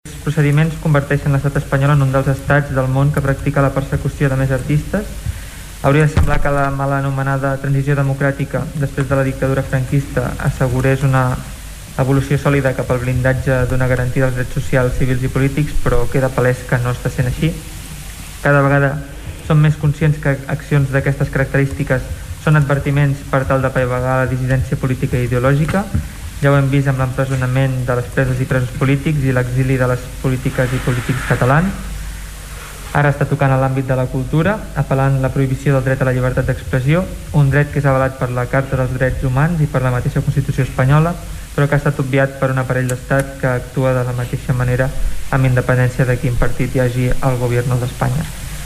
El text rebutjat l’empresonament del raper lleidatà i exigeix la derogació de la Llei Mordassa i una reformulació del codi penal. Escoltem el regidor de la CUP, Oriol Serra.